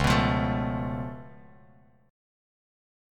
C#+7 chord